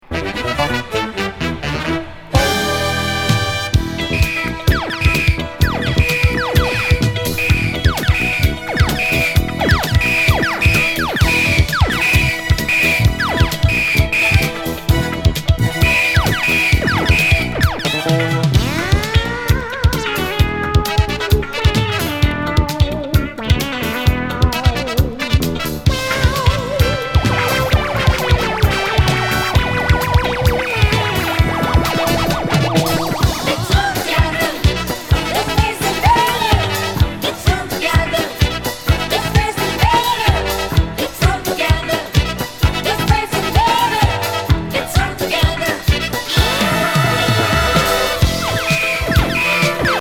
”インベーダー”ピコピコ・サンプリングのナイス国産ディスコ！！